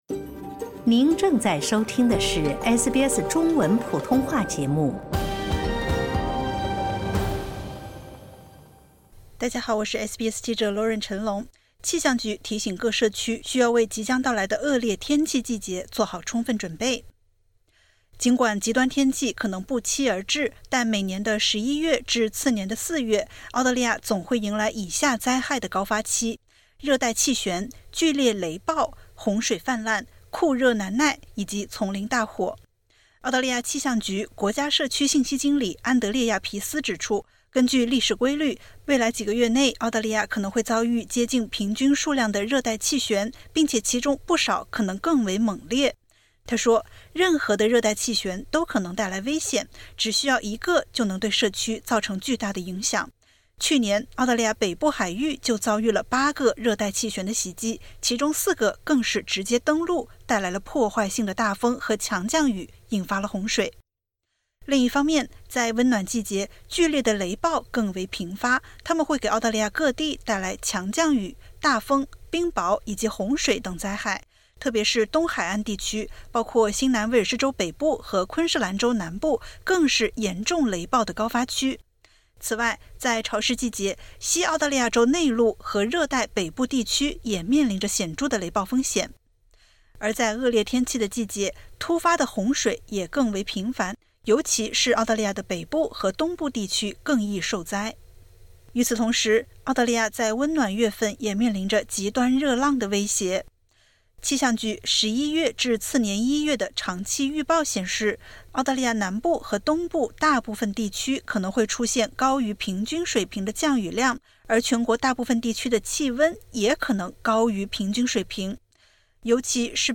气象局提醒各社区，需为即将到来的澳大利亚恶劣天气季节做好充分准备。点击 ▶ 收听完整报道。